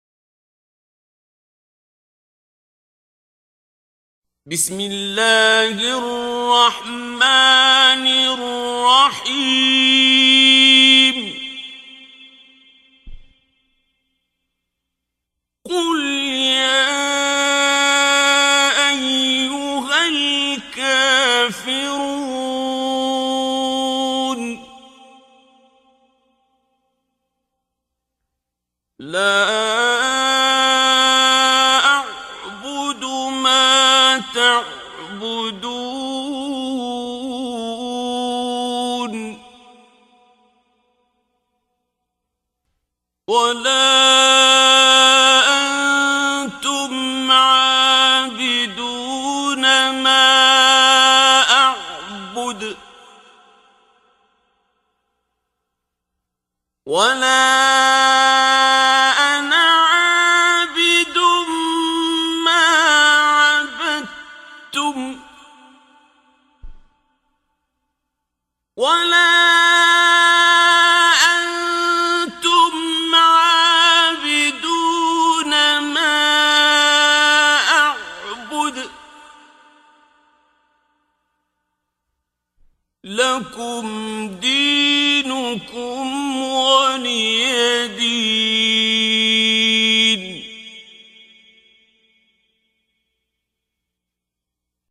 دانلود تلاوت زیبای سوره کافرون آیات 1 الی 6 با صدای دلنشین شیخ عبدالباسط عبدالصمد
در این بخش از ضیاءالصالحین، تلاوت زیبای آیات 1 الی 6 سوره مبارکه کافرون را با صدای دلنشین استاد شیخ عبدالباسط عبدالصمد به مدت 1 دقیقه با علاقه مندان به اشتراک می گذاریم.